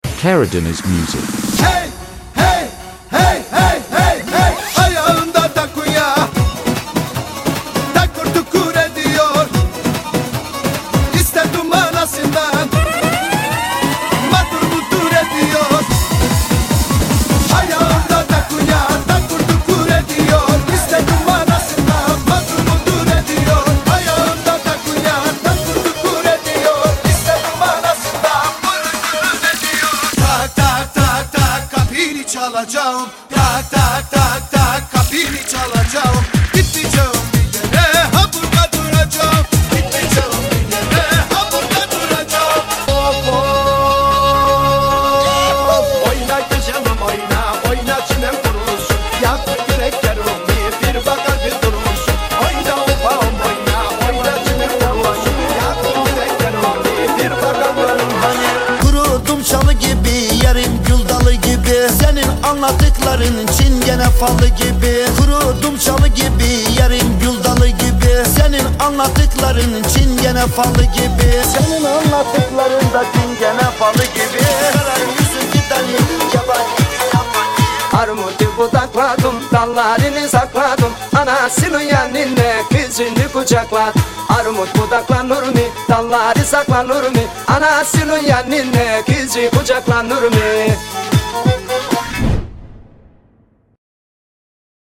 HORON KEMENÇE KEMENCE